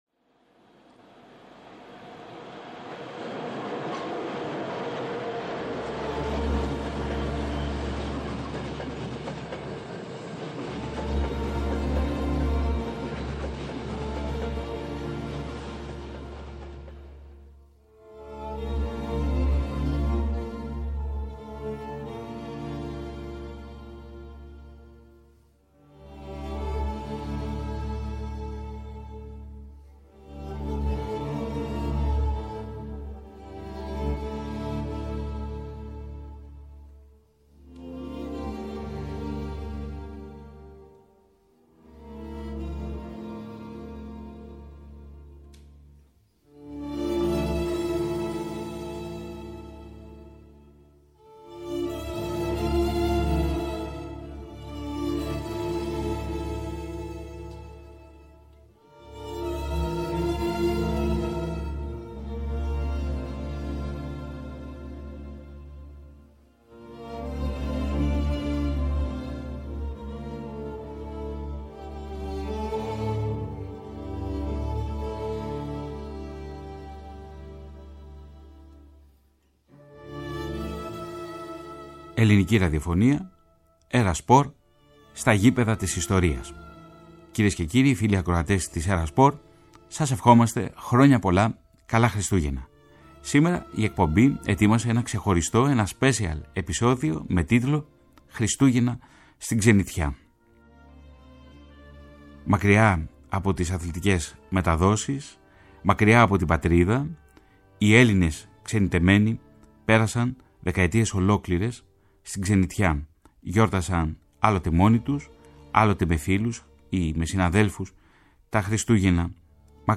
Γράμματα και αφηγήσεις ξενιτεμένων μακριά από την αγαπημένη τους πατρίδα, τις πατρογονικές τους εστίες, τους συγγενείς και τους παιδικούς τους φίλους. Ένα ταξίδι στα ύστερα μεταπολεμικά χρόνια, τότε που η μόνη διέξοδος για τη ζωή πολλών ανθρώπων ήταν η φυγή σε άλλους τόπους. Ένα συρτάρι με αναμνήσεις, με σπάνιες συνεντεύξεις και ηχητικά τεκμήρια.